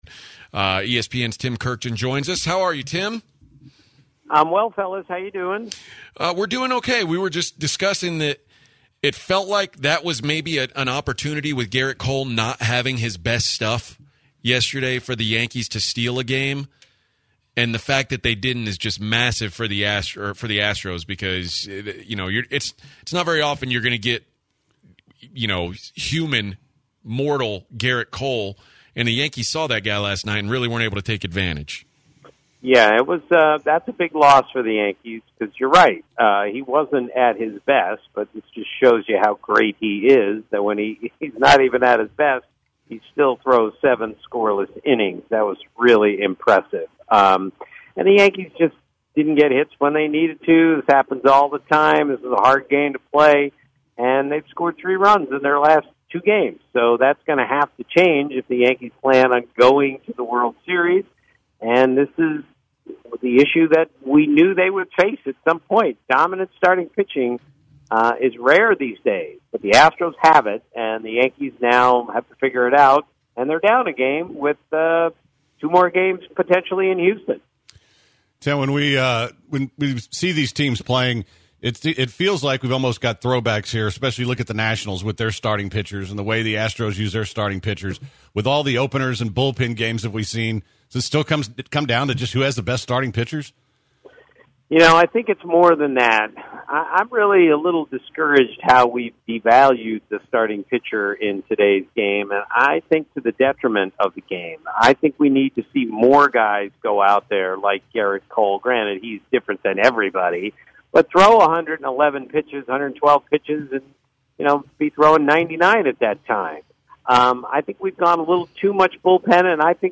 10/16/2019 Interview with Tim Kurkjian
ESPN's Tim Kurkjian joins the show to talk about Game 3 and the ALCS